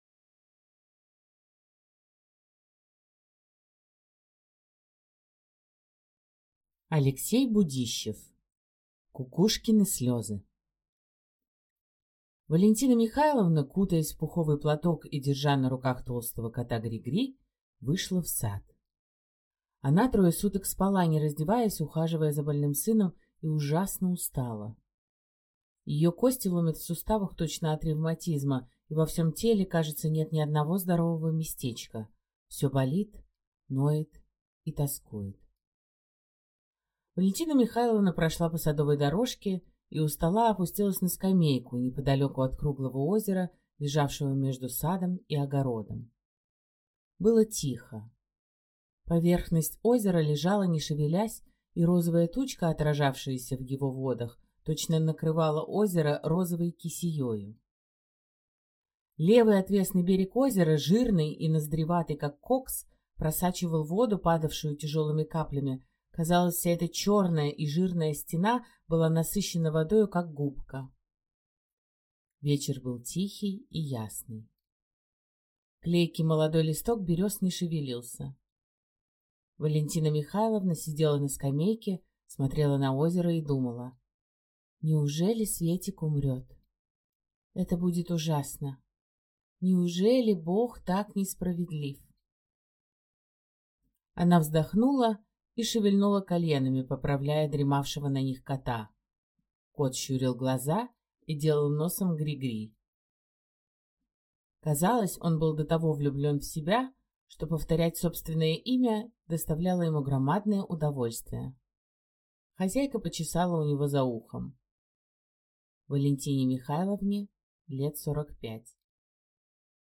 Аудиокнига Кукушкины слезы | Библиотека аудиокниг
Прослушать и бесплатно скачать фрагмент аудиокниги